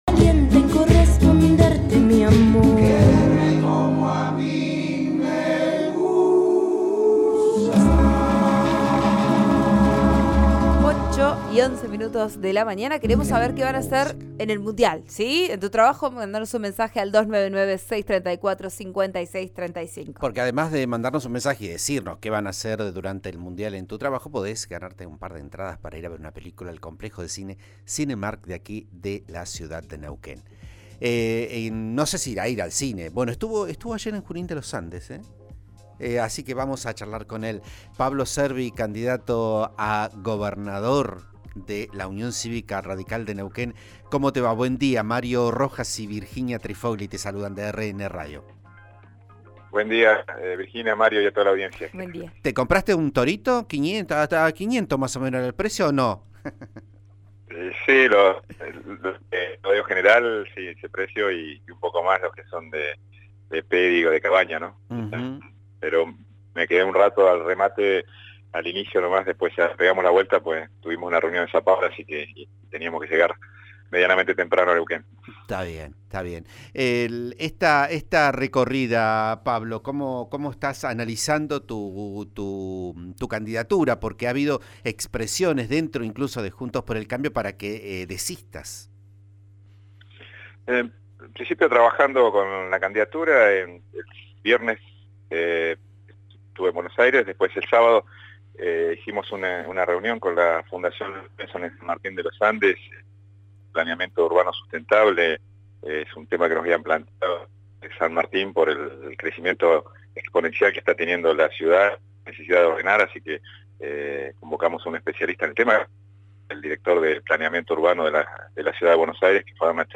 Cervi habló con Vos A Diario por RN Radio.